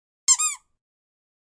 Squeak Sound Effect Free Download
Squeak